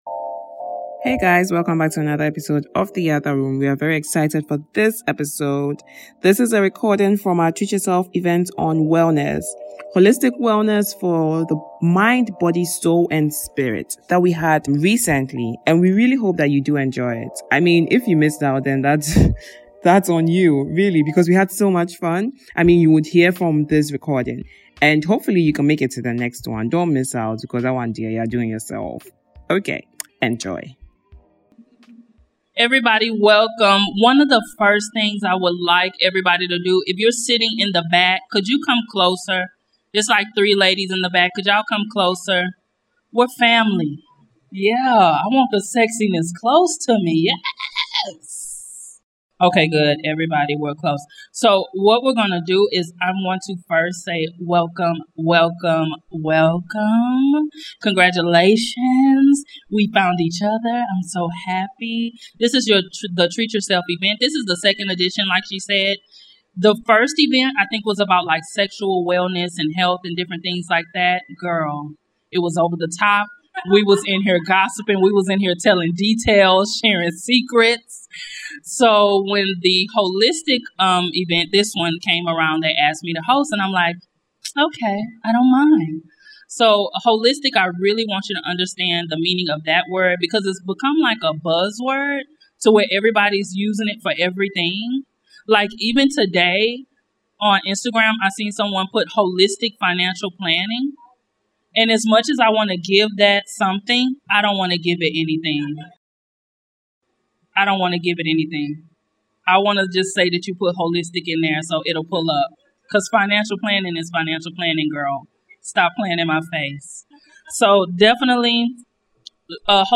A women led discussion on Holistic Wellness for Mind, Body, Spirit and Soul.